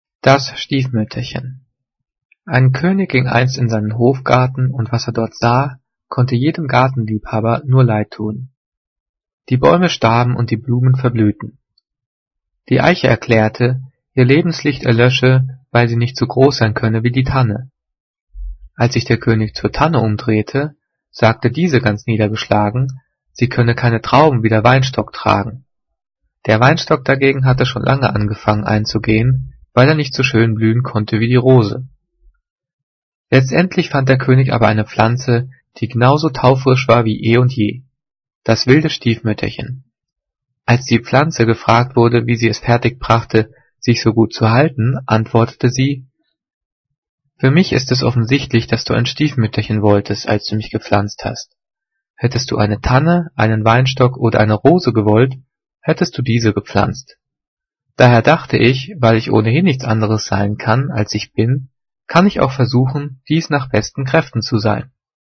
Diktat: "Das Stiefmütterchen" - 5./6. Klasse - Getrennt- und Zus.
Gelesen:
gelesen-das-stiefmuetterchen.mp3